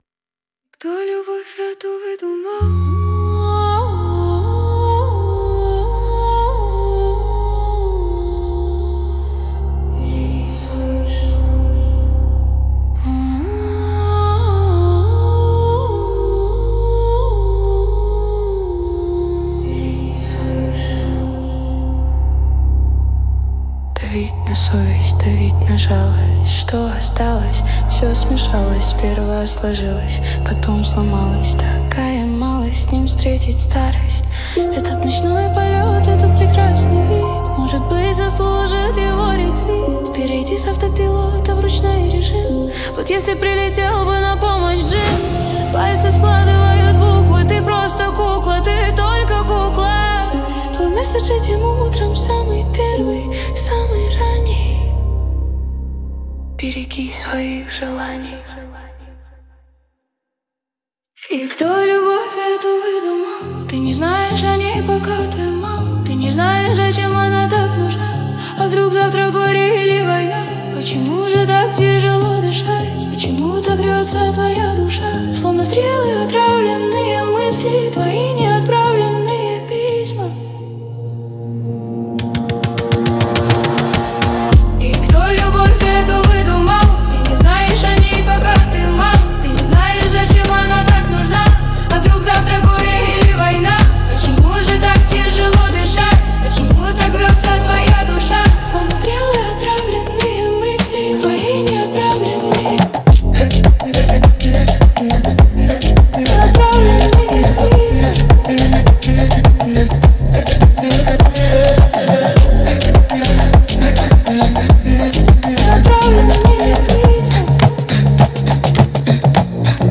Качество: 192 kbps, stereo
Ремиксы, 2026